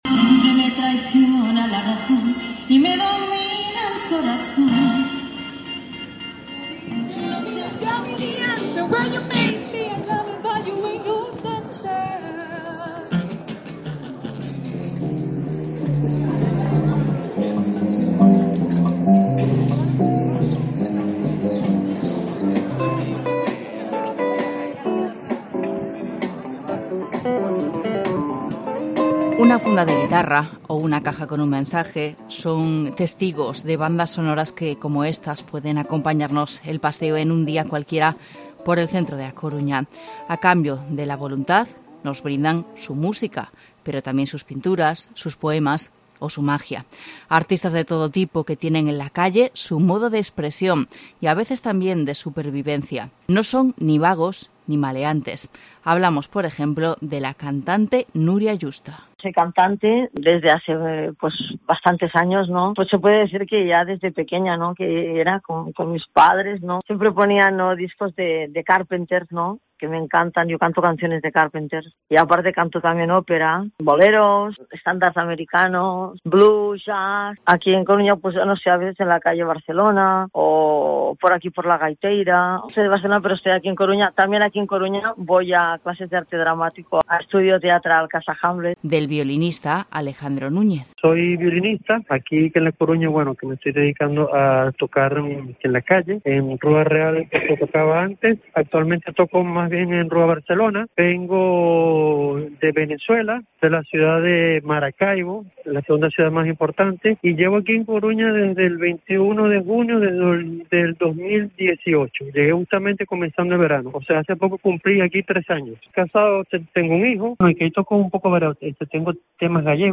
Reportaje artistas callejeros en A Coruña